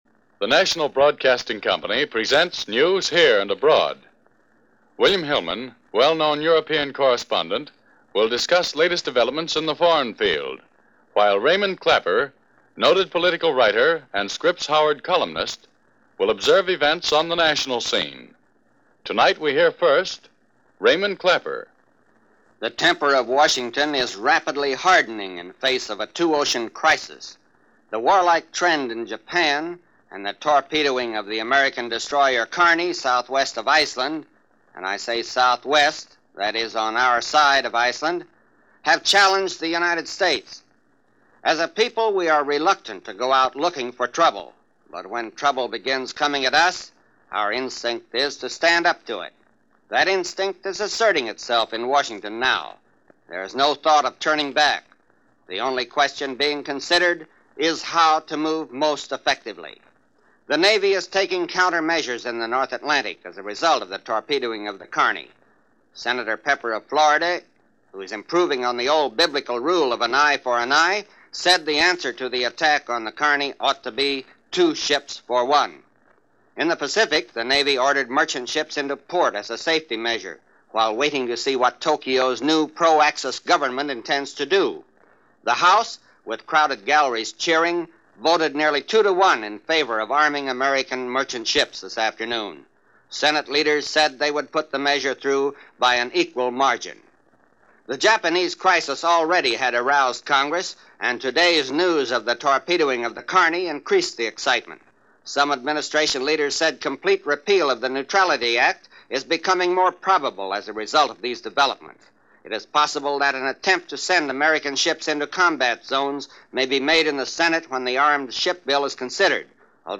NBC Blue Network – News Here and Abroad